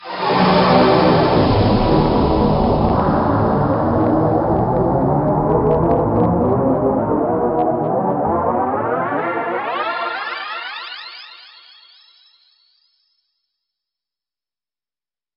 描述：用REAKTOR制作的古怪循环和垫子
Tag: 125 bpm Weird Loops Fx Loops 1.75 MB wav Key : Unknown